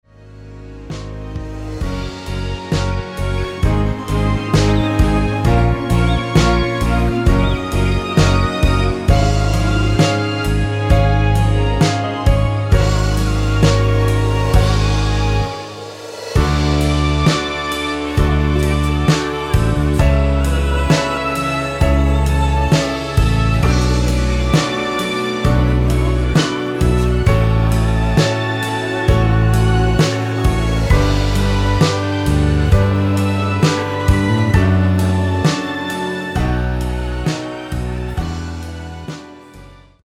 뒷부분 코러스 포함된 MR 입니다.(미리듣기 참조)
Db
앞부분30초, 뒷부분30초씩 편집해서 올려 드리고 있습니다.
중간에 음이 끈어지고 다시 나오는 이유는